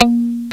Index of /m8-backup/M8/Samples/Fairlight CMI/IIX/GUITARS